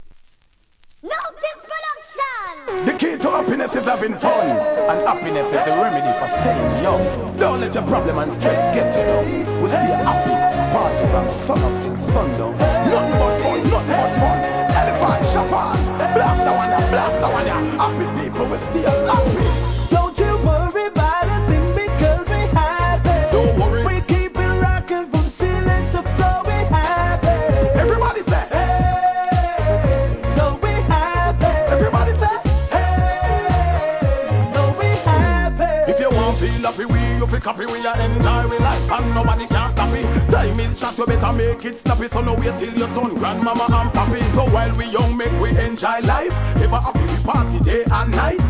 REGGAE
2010年リリースのDISCO調ノリノリRHYTHM!!